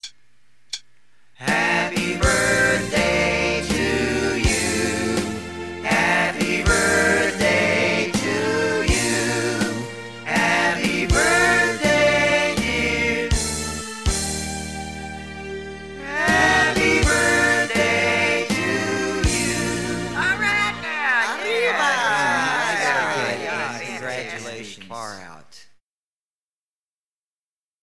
Happy Birthday (Live Performance).m4a